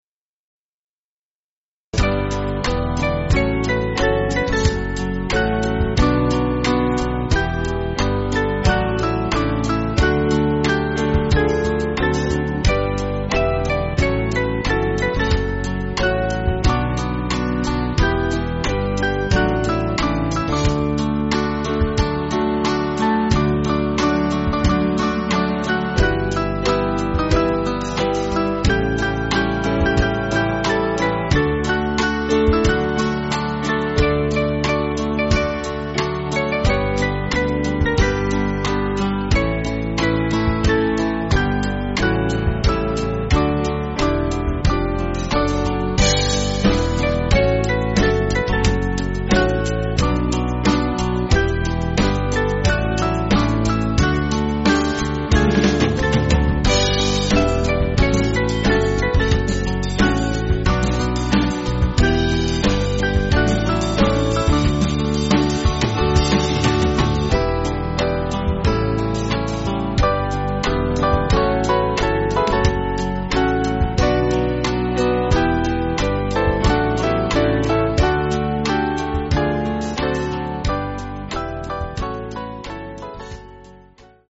Catholic hymn
Small Band